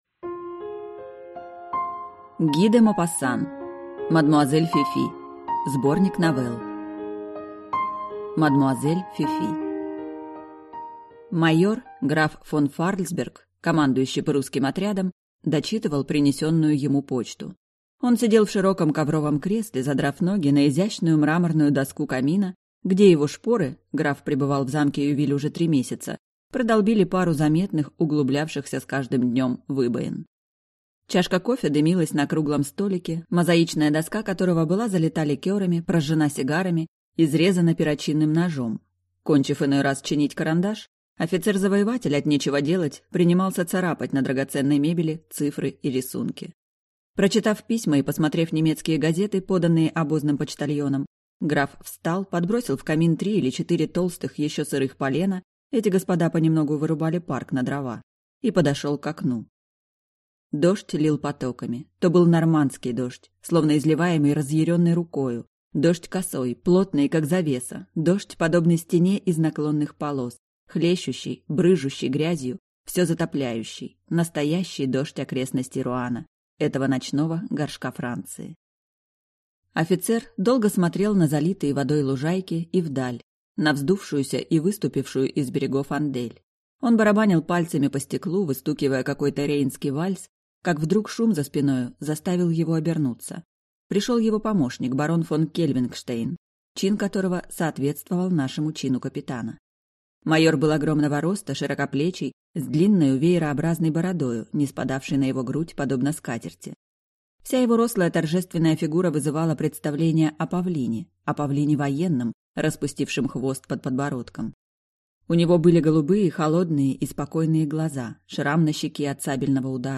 Аудиокнига Мадмуазель Фифи. Сборник новелл | Библиотека аудиокниг